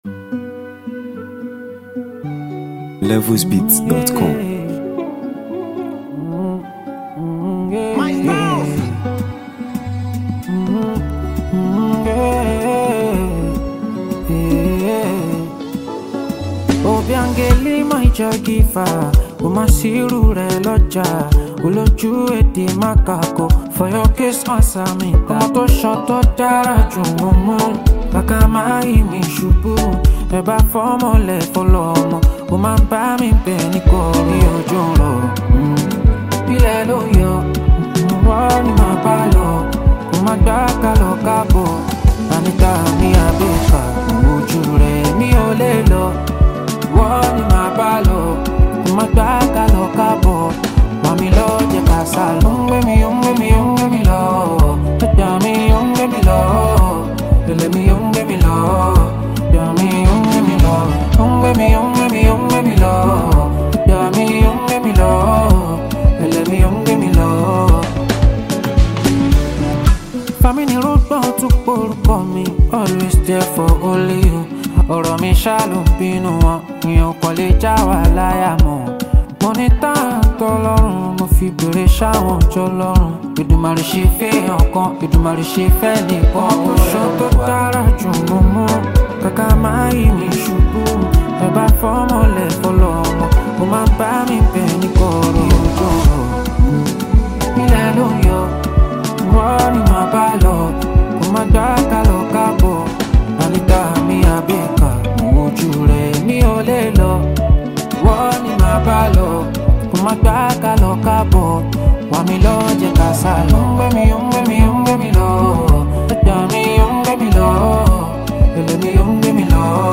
the highly gifted Nigerian afrobeat singer
With its infectious rhythm and memorable vibe